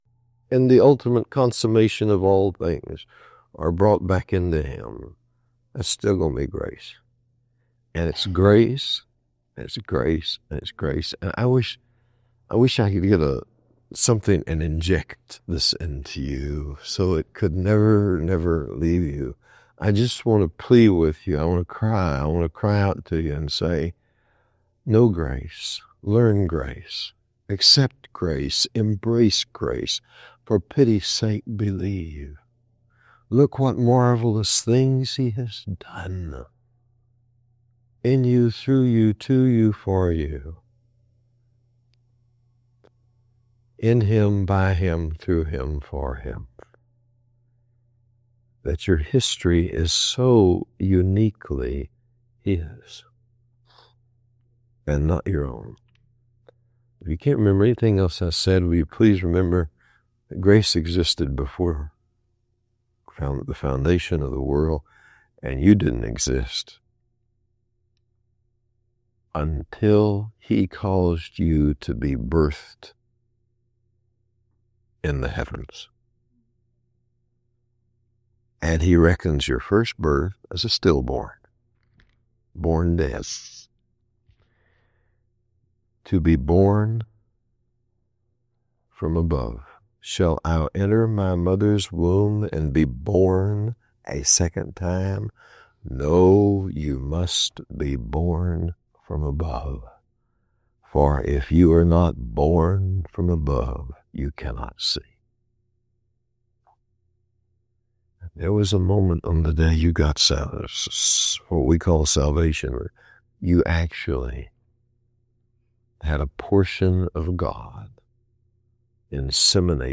This powerful message challenges the popular 200-year-old tradition that prioritizes spiritual gifting over the simple, organic life of Christ. The speaker asserts that true spiritual service does not come from focusing on natural talents, which must first be broken at the cross, but from the divine life that emerges through daily surrender.